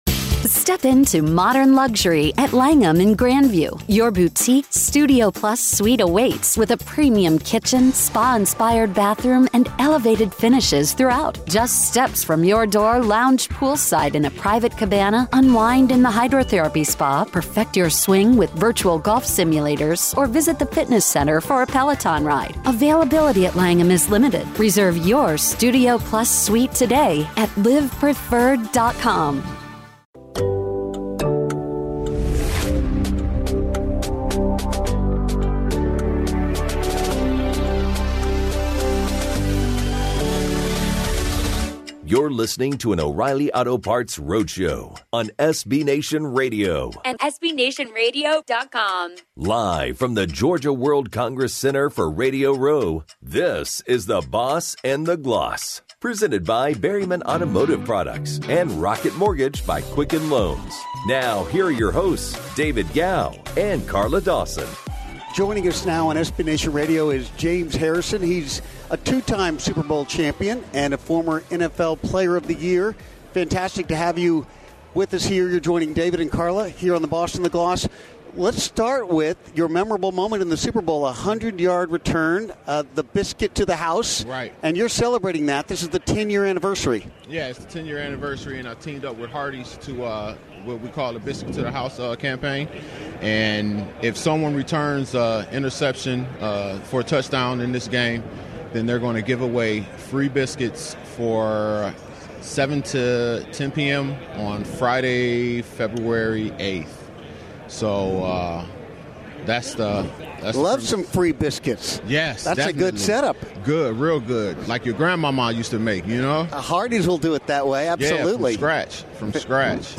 The Boss & The Gloss SBLIII Radio Row Guest: former Steelers LB James Harrison